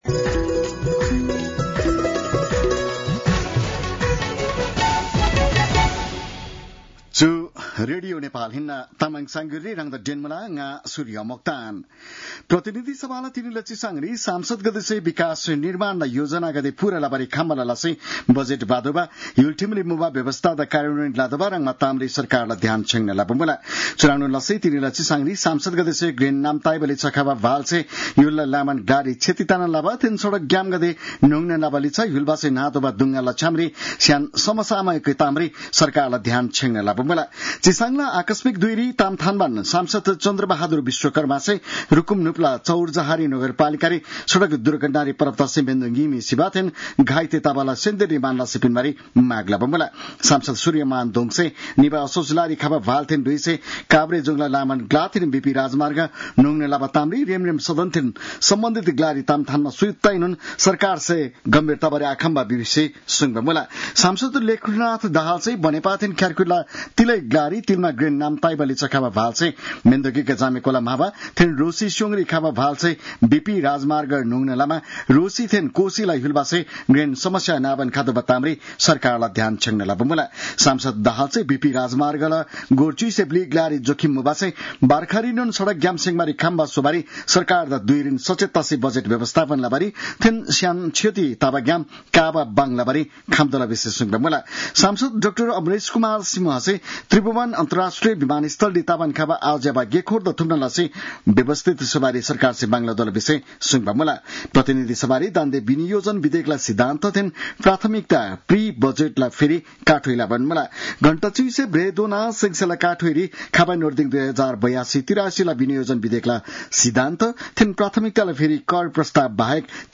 तामाङ भाषाको समाचार : ३० वैशाख , २०८२